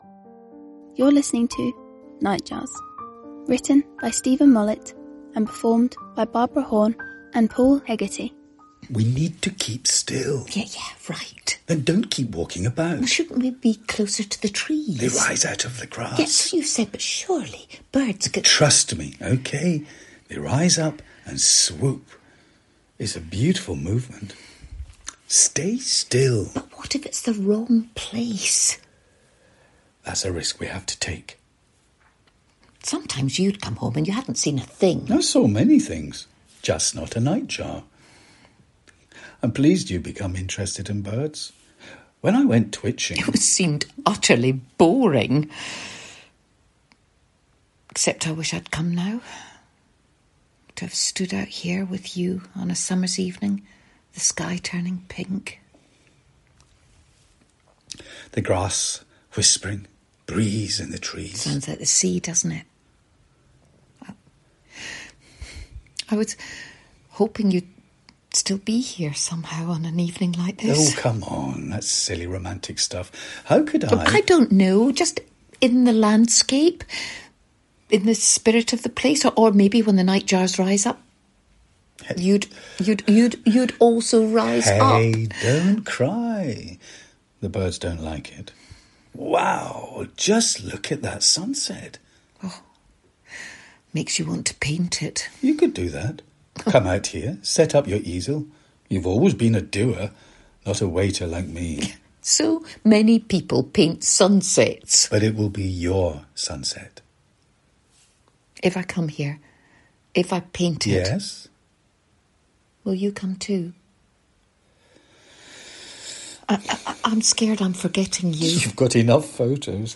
A pair of birdwatchers gently chide each other at twilight.